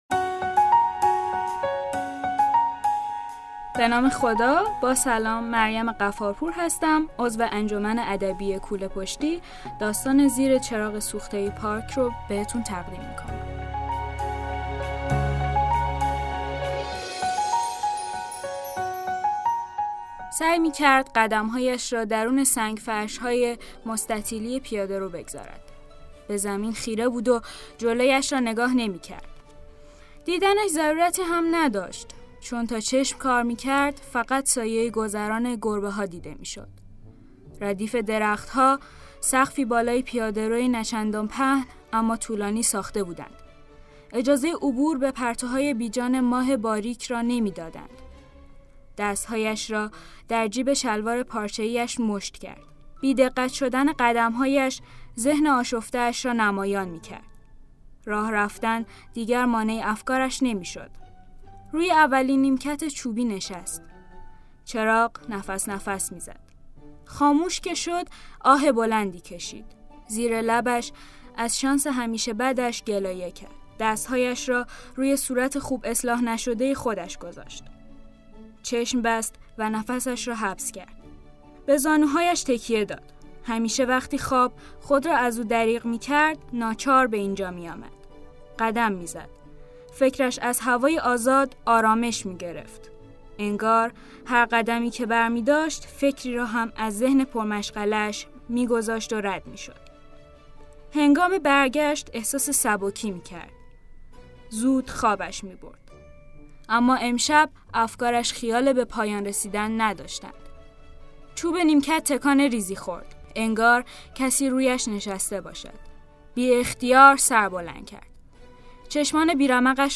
داستان کودک و نوجوان «چراغ سوخته‌ی پارک